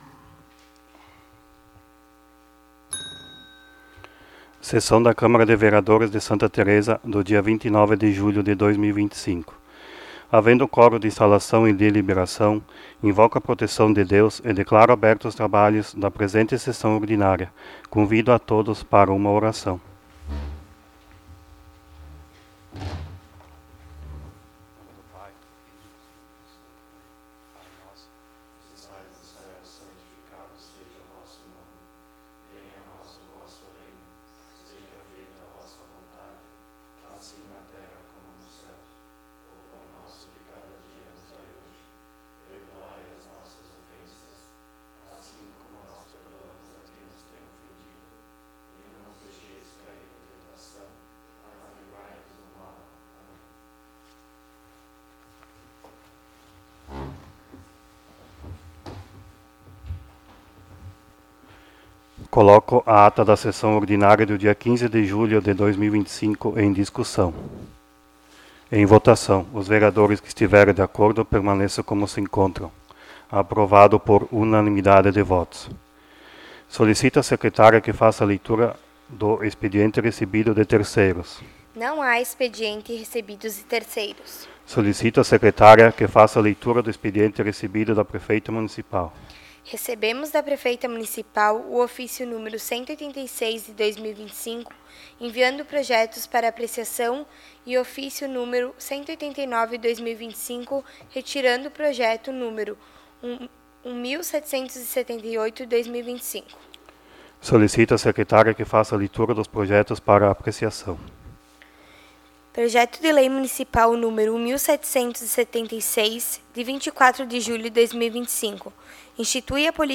12° Sessão Ordinária de 2025
Áudio da Sessão